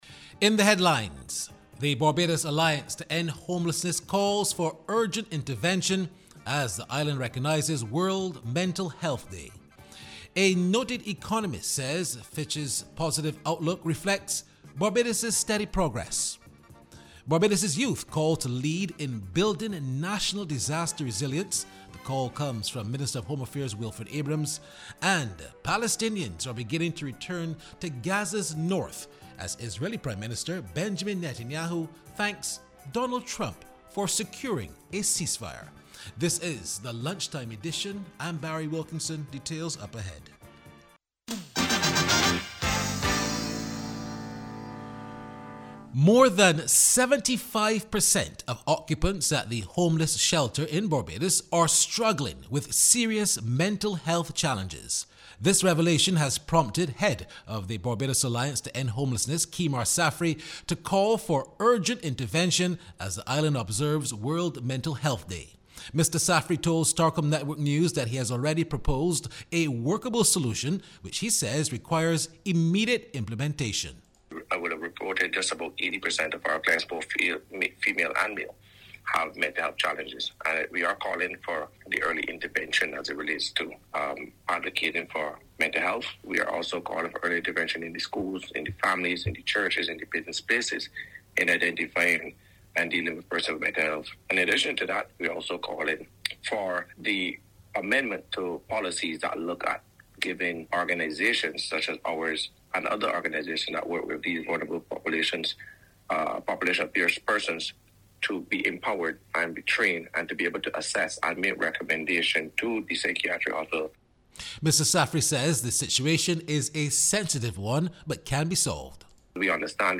He made this disclosure while speaking during the Central Bank of Barbados’ review of the country’s economic performance for January to June this year.